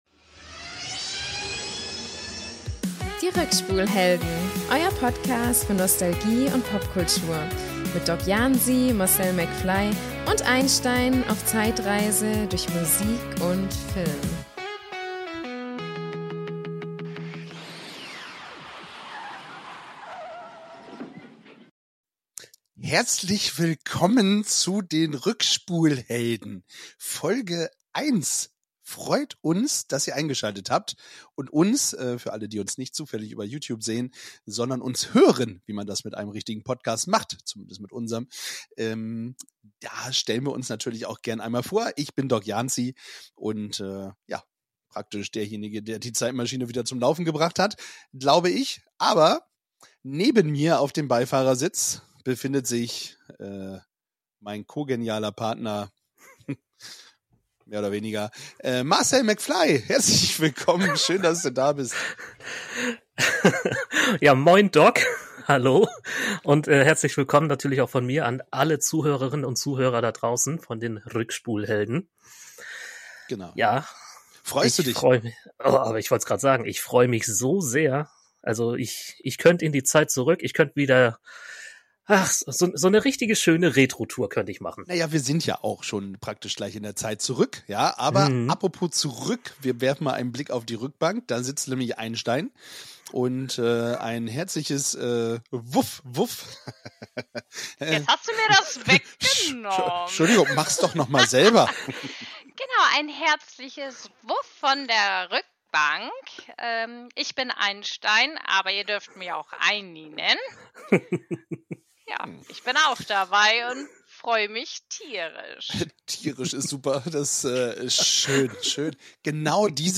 Ein Ratespiel mit Musiktexten sorgt für Unterhaltung, während die Moderatoren über Herzschmerz und persönliche Erinnerungen reflektieren.